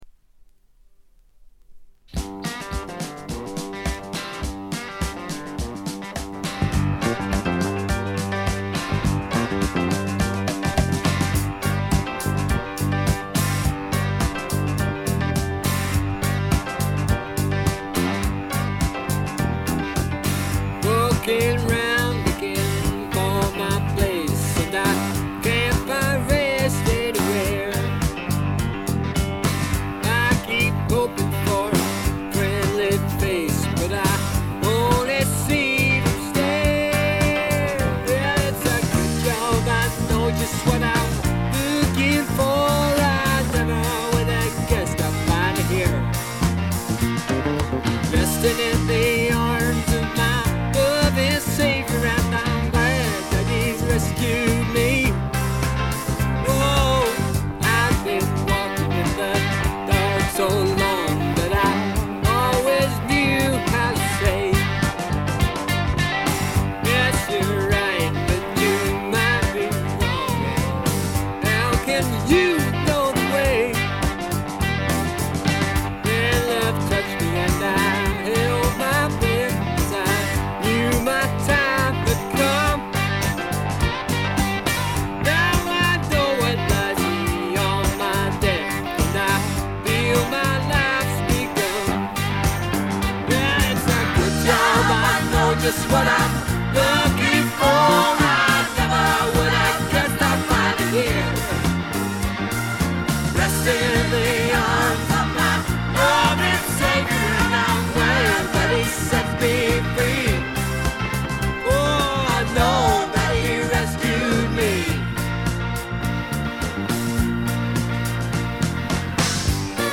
ホーム > レコード：英国 スワンプ
部分試聴ですが、微細なノイズ感のみ。
英国シンガーソングライター／スワンプの基本中の基本！
試聴曲は現品からの取り込み音源です。